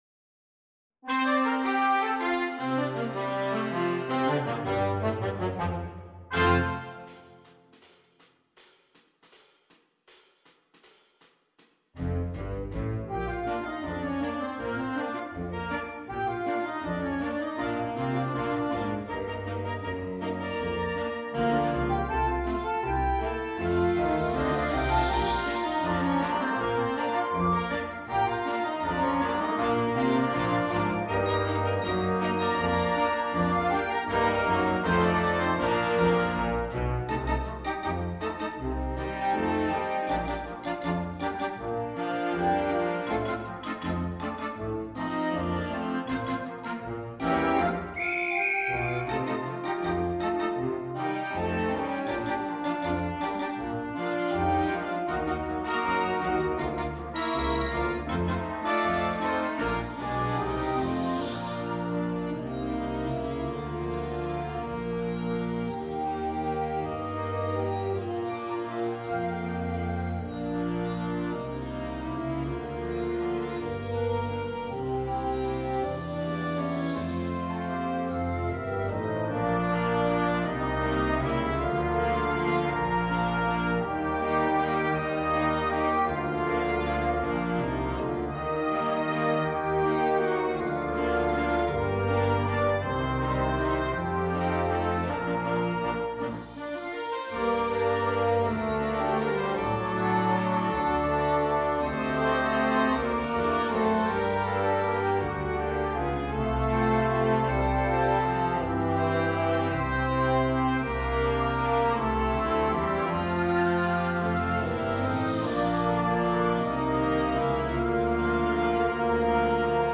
Gattung: Für Flexible Besetzung
Besetzung: Blasorchester